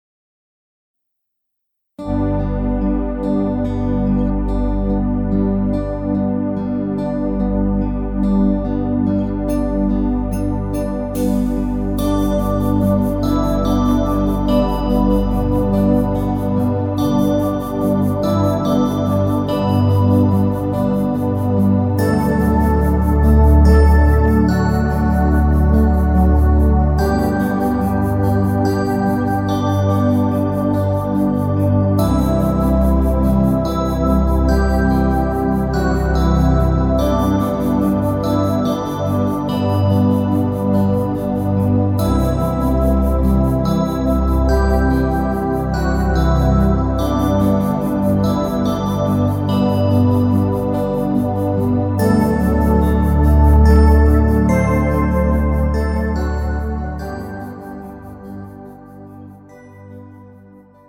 음정 G 키
장르 가요 구분 Pro MR
Pro MR은 공연, 축가, 전문 커버 등에 적합한 고음질 반주입니다.